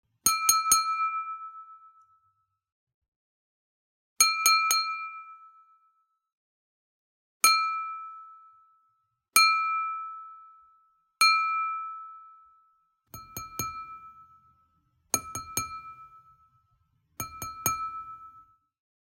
Wine Glass Toast Clink
attention Bell Ding Glass tap Ting Toast Wine sound effect free sound royalty free Sound Effects